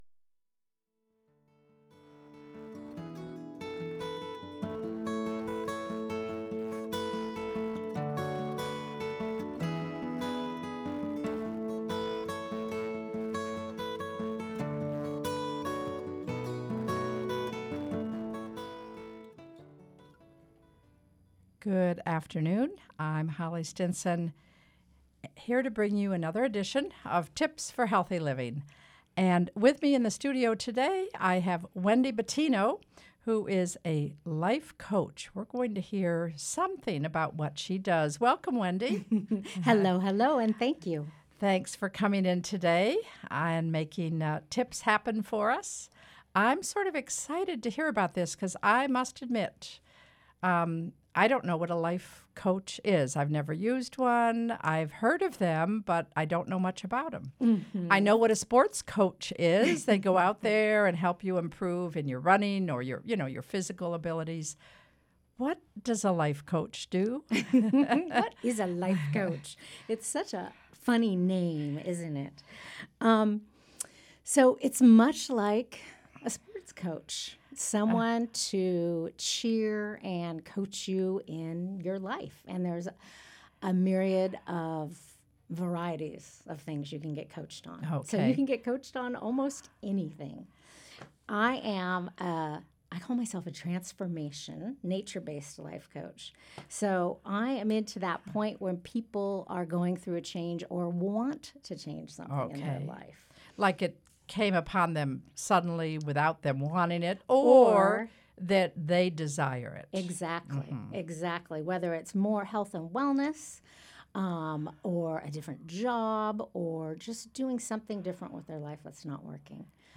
A live 15-minute conversation about health and wellness